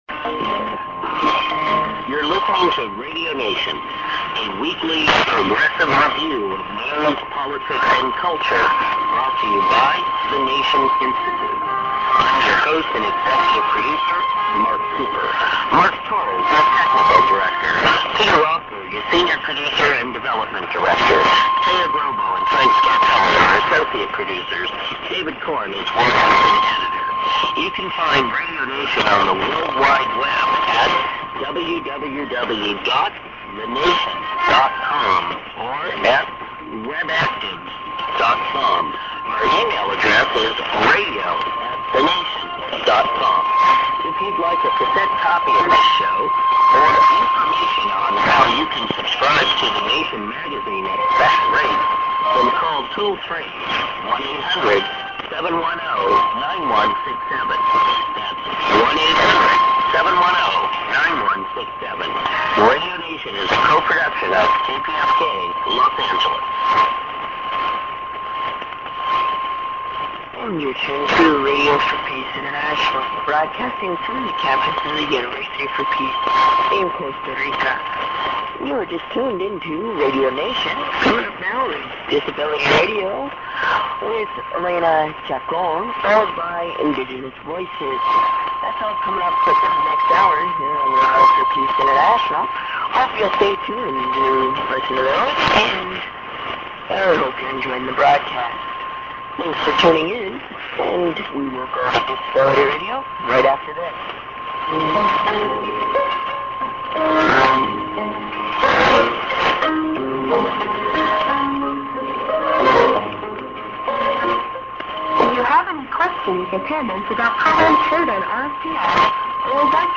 ->Call+Web Addr->ANN(man:Radio the Nation)->ID+ADDR(women)->　＊別の局の中継か